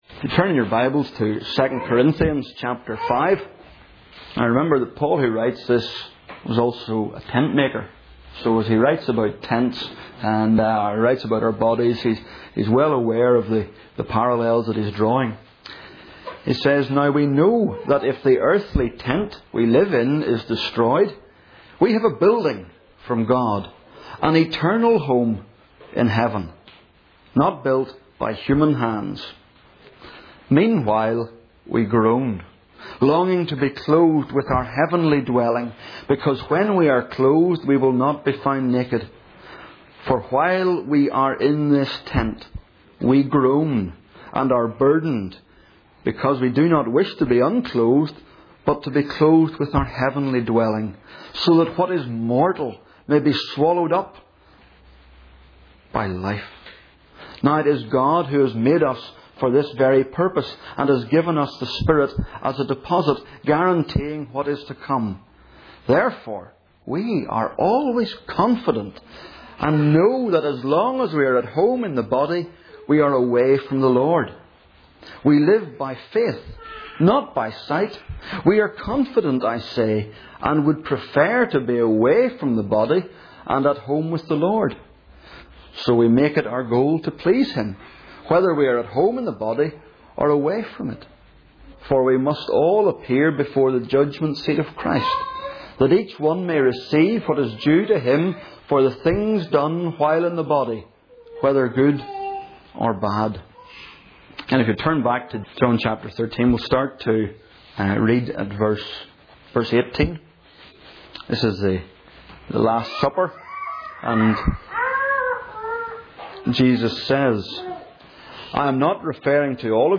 John | Single Sermons | new life fellowship